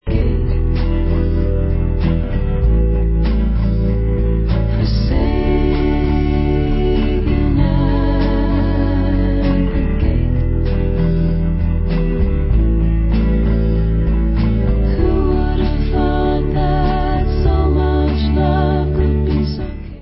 =2001 studio album=